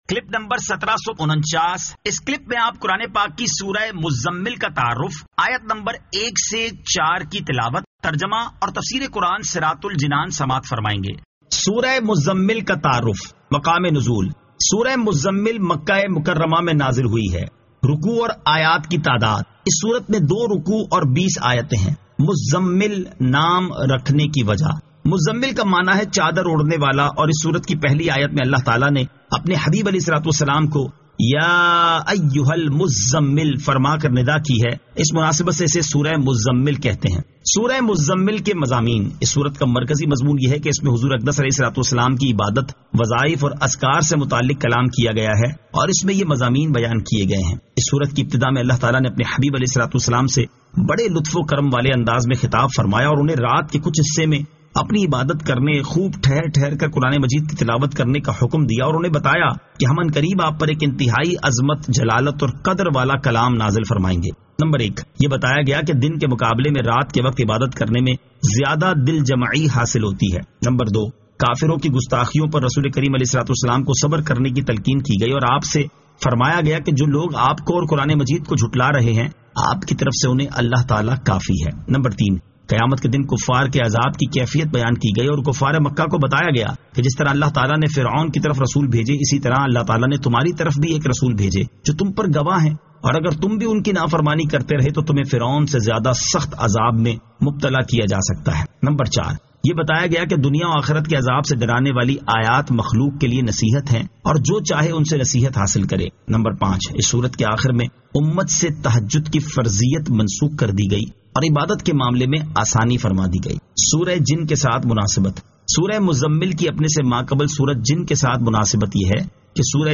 Surah Al-Muzzammil 01 To 04 Tilawat , Tarjama , Tafseer